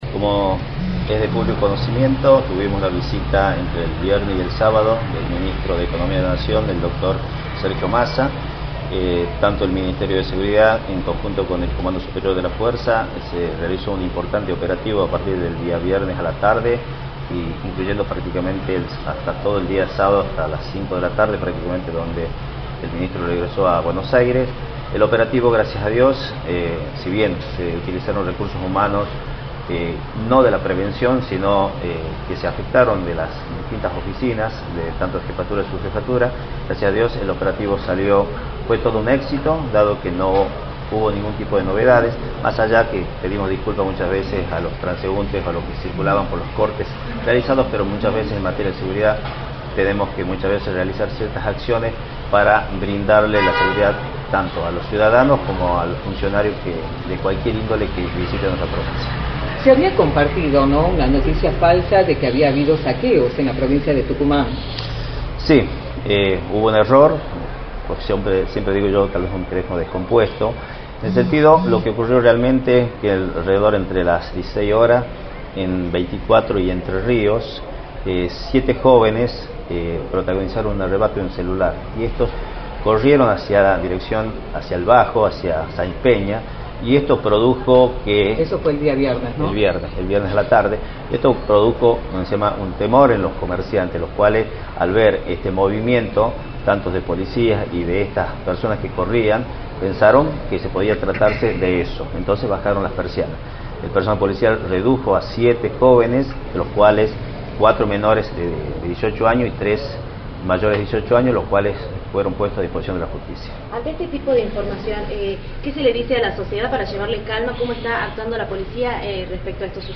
“Se realizó un importante operativo a partir del día viernes a la tarde hasta todo el sábado a las 17 de la tarde y el operativo fue todo un éxito dado que no hubo ningún tipo inconveniente, pedimos disculpas a los transeúntes que circulaban por los cortes realizados” señaló Rolando Gómez en entrevista para “La Mañana del Plata”, por la 93.9.